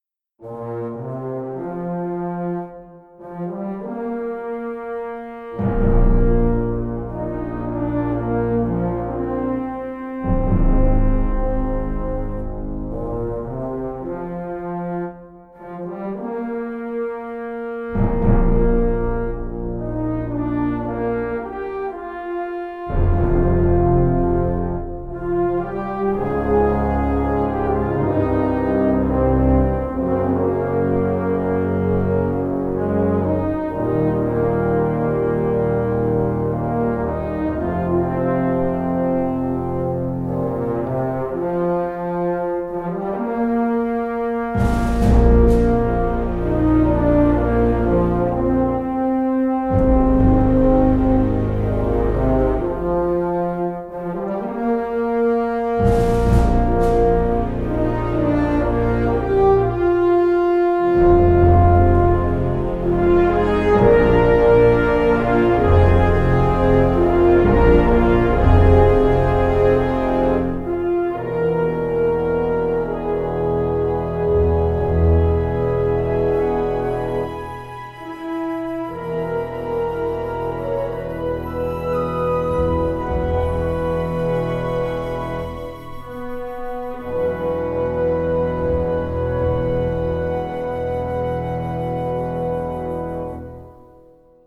它由 3 个大号和 3 个上低音号组成，是一个温暖、圆润的低音铜管音色库。
它还具有真正的连奏功能，可以演奏流畅的旋律线 。